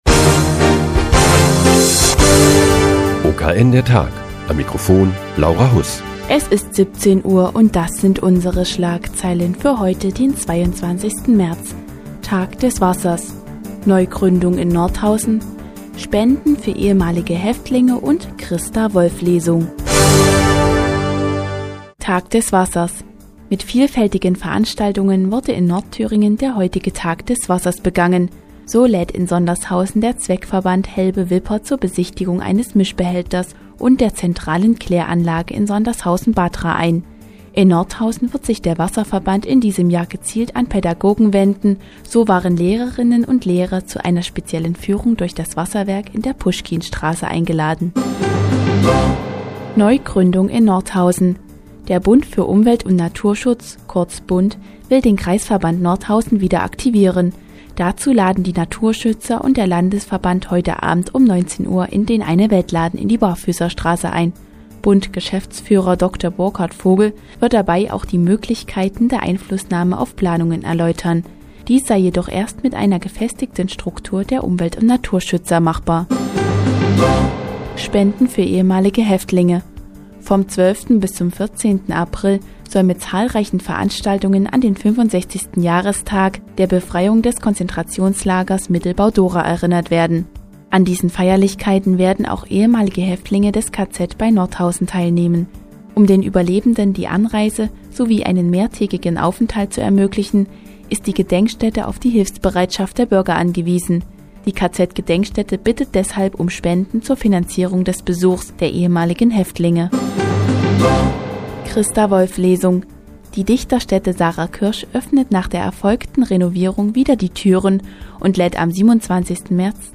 Die tägliche Nachrichtensendung des OKN ist nun auch in der nnz zu hören. Heute geht es um den "Tag des Wassers" und eine Lesung in der Dichterstätte Sarah Kirsch.